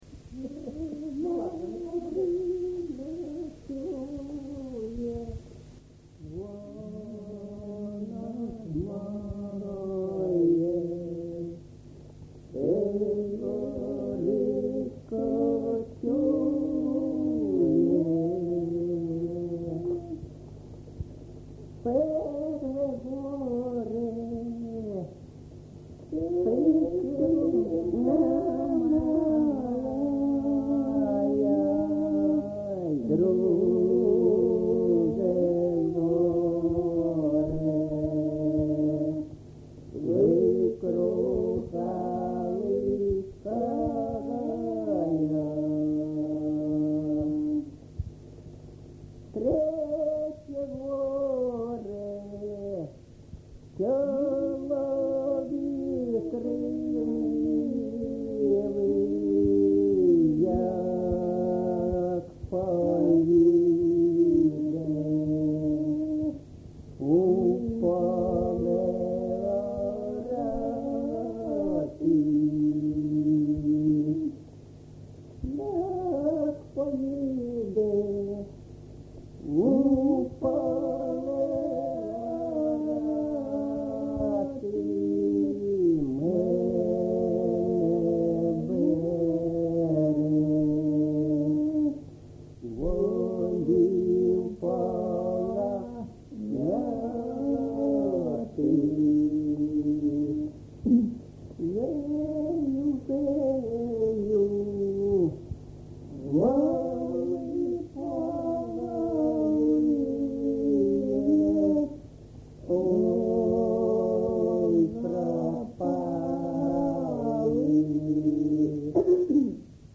ЖанрПісні з особистого та родинного життя
Місце записум. Часів Яр, Артемівський (Бахмутський) район, Донецька обл., Україна, Слобожанщина